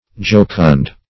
Jocund \Joc"und\, [L. jocundus, jucundus, orig., helpful, fr.